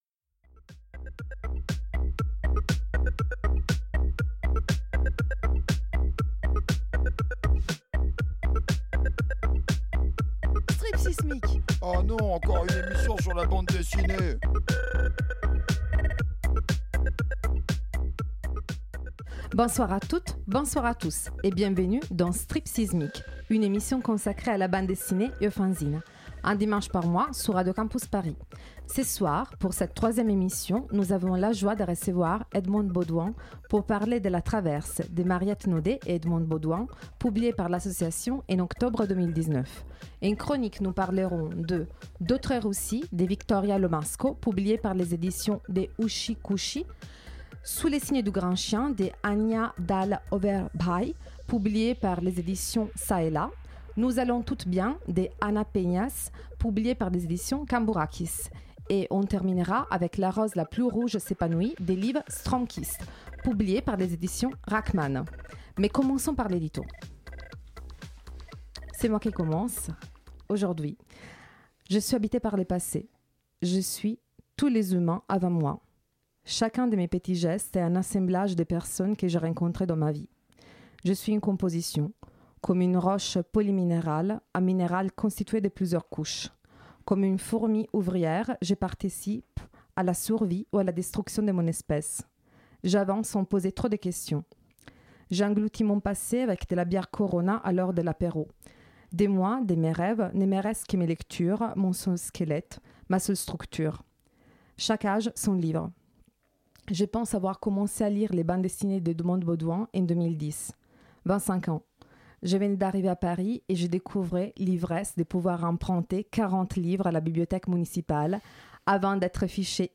Dimanche 1er décembre, c'était Edmond Baudoin qui nous avons eu le plaisir de recevoir dans les studios pour parler de La Traverse co-écrit avec Mariette Nodet et publiée par l'Association en octobre 2019.